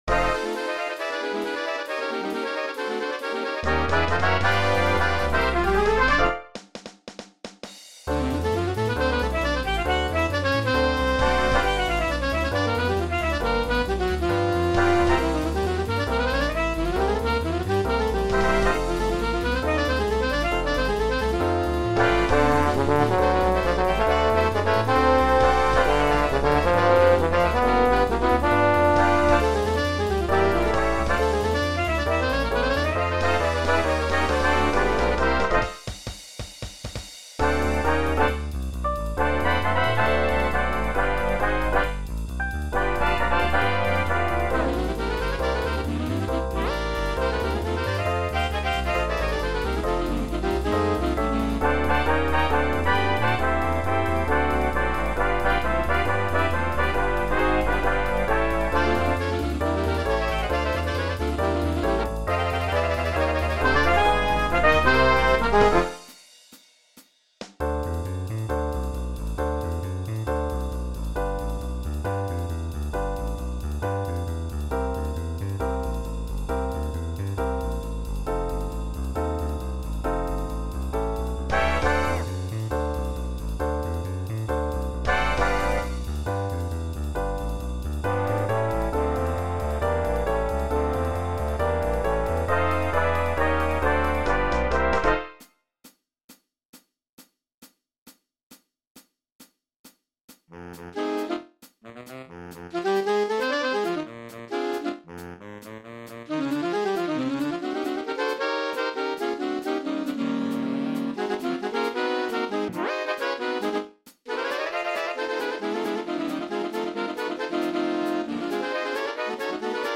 Jazz Band - JazzTones
Standard Jazz Ensemble
Style: Fast BeBop
is a sax section feature on steroids.